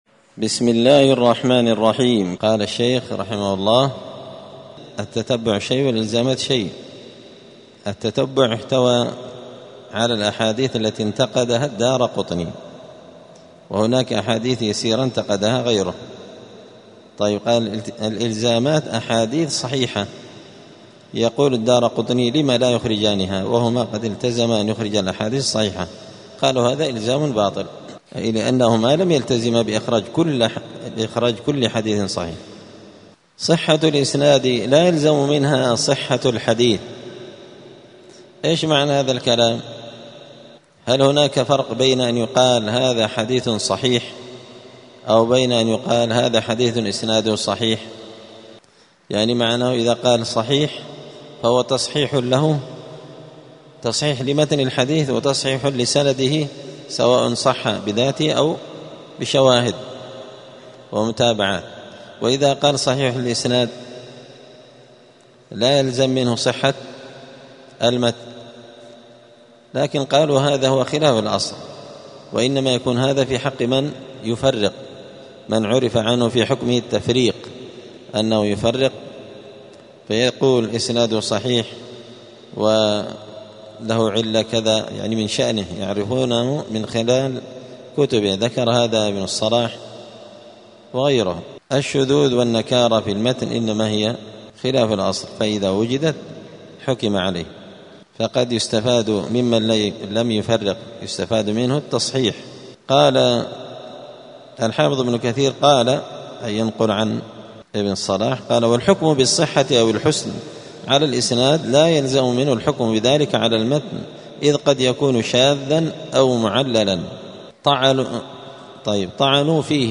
دار الحديث السلفية بمسجد الفرقان قشن المهرة اليمن
22الدرس-الثاني-والعشرون-من-السير-الحثيث.mp3